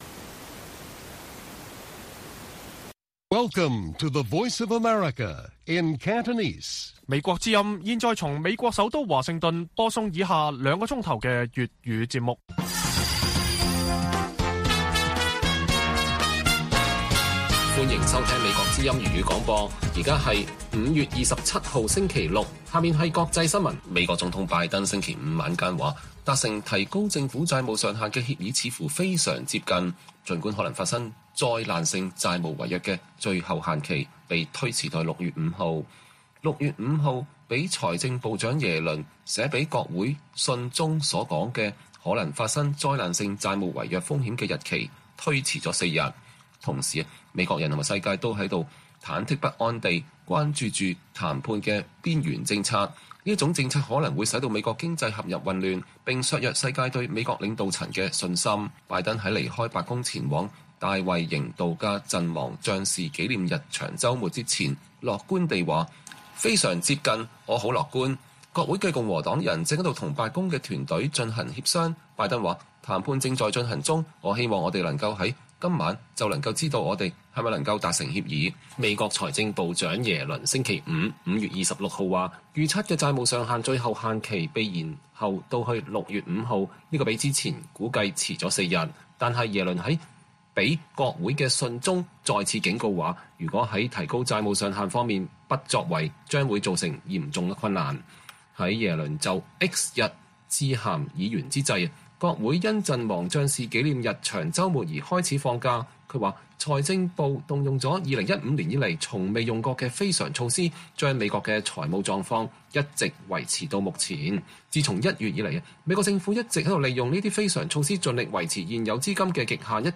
粵語新聞 晚上9-10點 : 美中關係如何解凍？